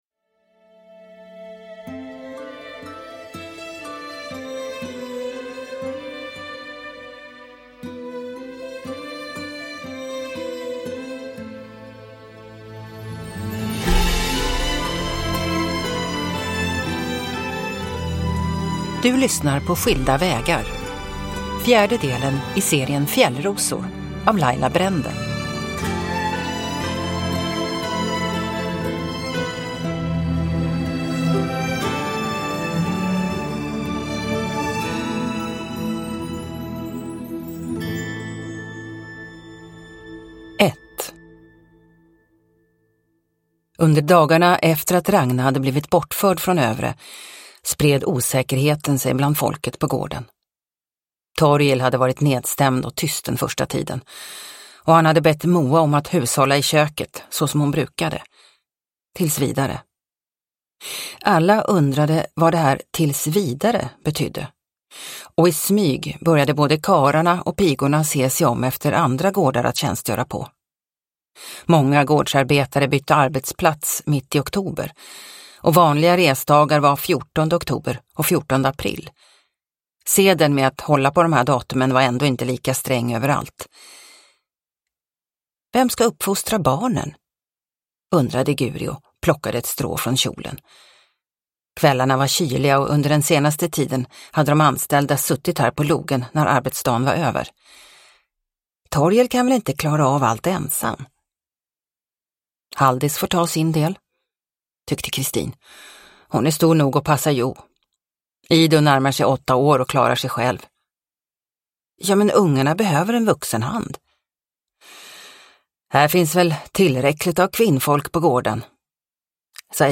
Skilda vägar – Ljudbok – Laddas ner